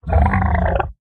Minecraft Version Minecraft Version snapshot Latest Release | Latest Snapshot snapshot / assets / minecraft / sounds / mob / zoglin / idle3.ogg Compare With Compare With Latest Release | Latest Snapshot